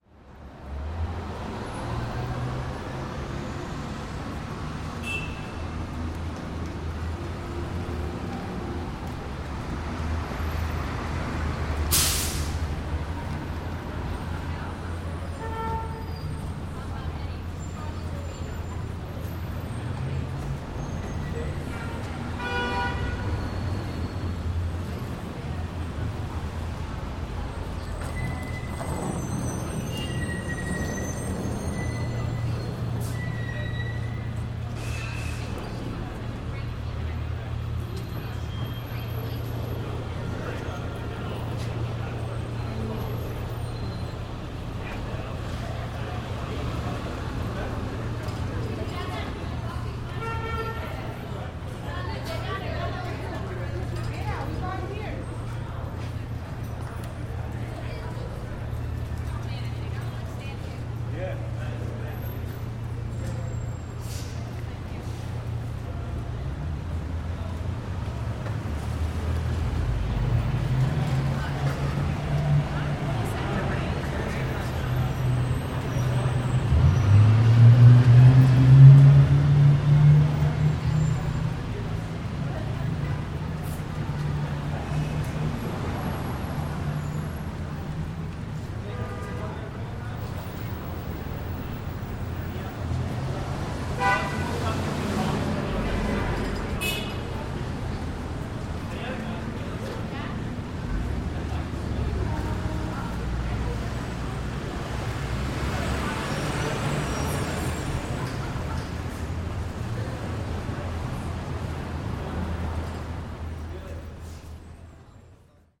Звуки автовокзала
Шум улицы перед отправлением автобуса с автовокзала